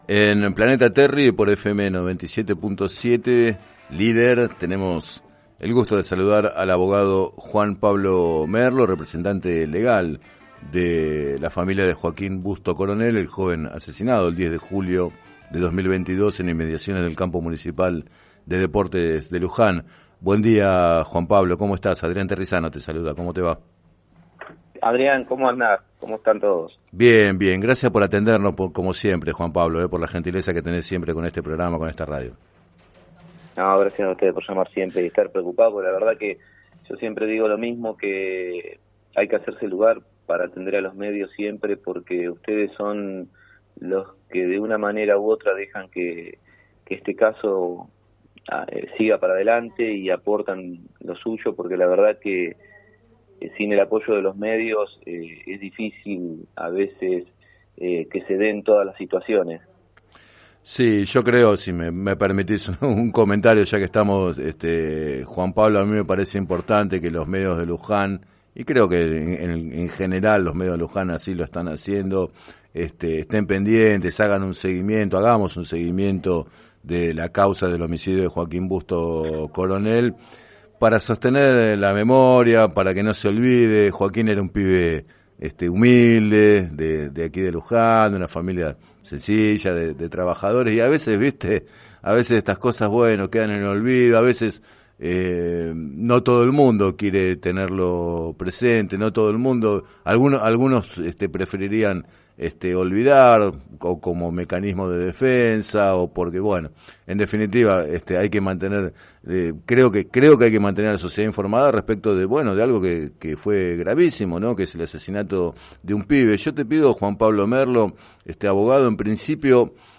En declaraciones al programa Planeta Terri de FM Líder 97.7